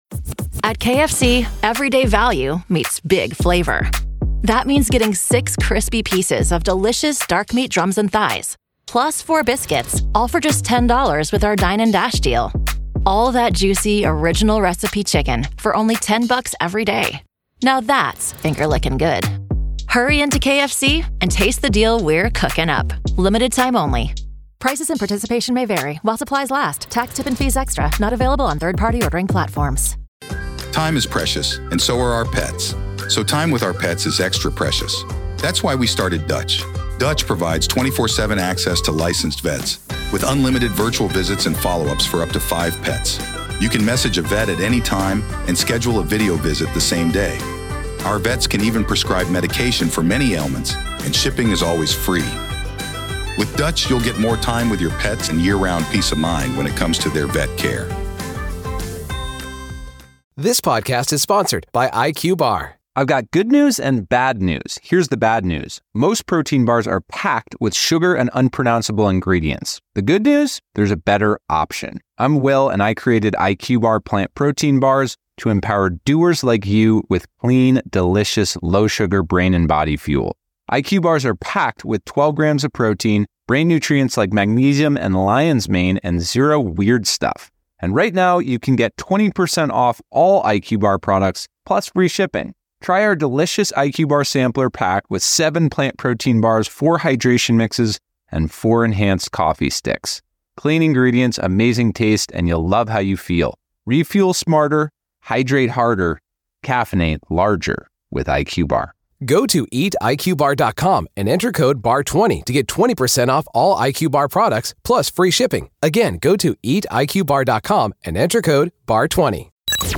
Main Points of the Conversation - Anticipation of new evidence and potential alternative suspects in Chad Daybell’s trial.